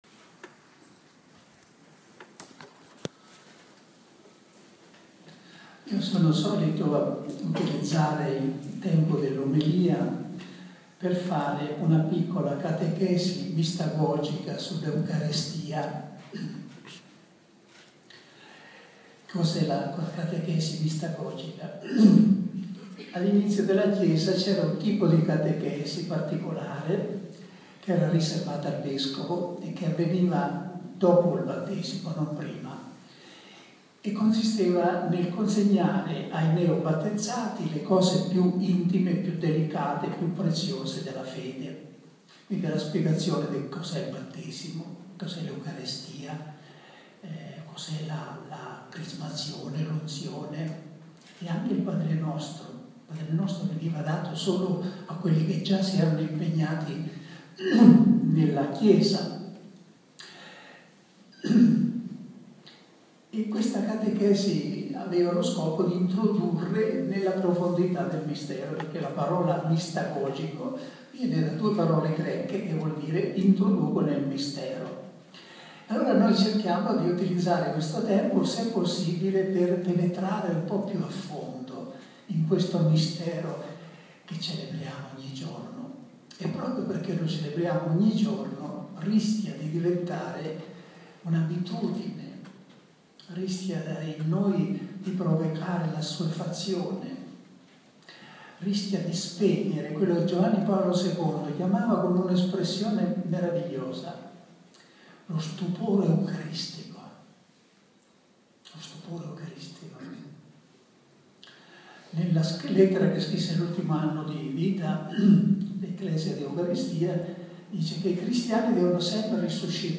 esercizi spirituali per i sacerdoti della diocesi di Bari predicatida Padre Raniero Cantalamessa dal 23 al 28 ottobre presso l’oasi Santa Maria di Cassano delle Murge